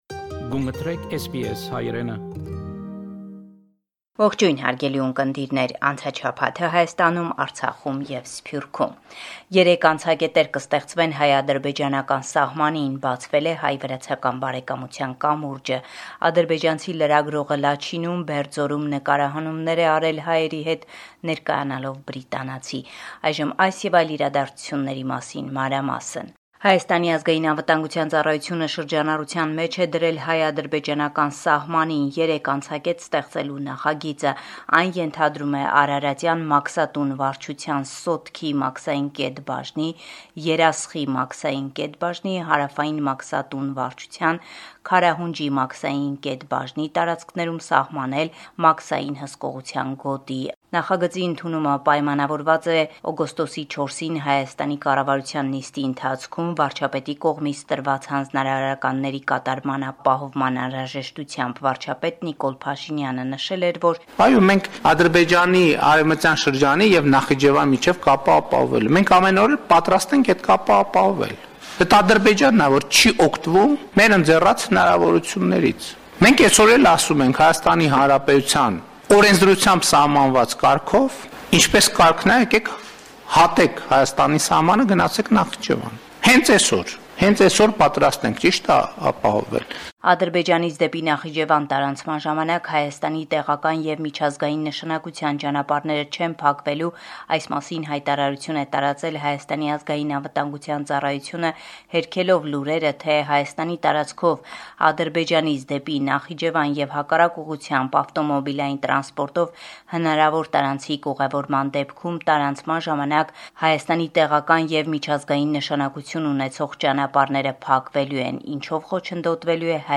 Latest News from Armenia – 23 August 2022